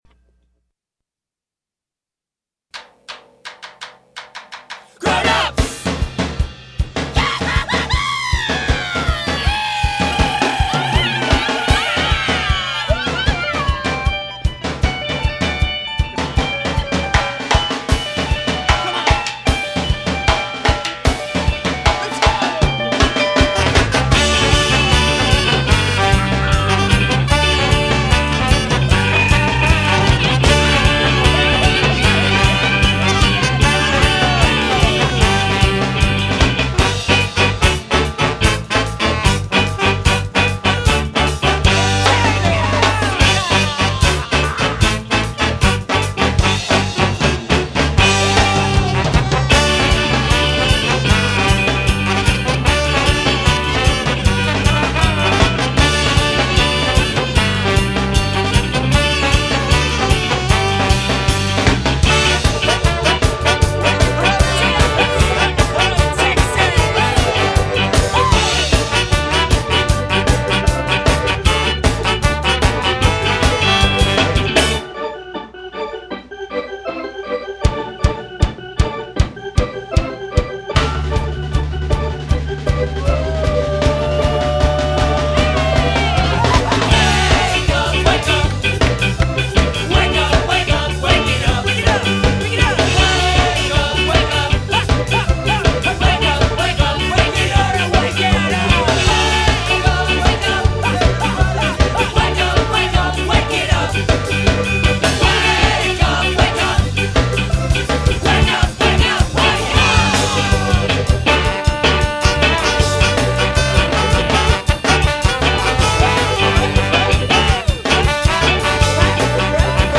Recorded live at 70Hurtz studio in Argyle, TX 1996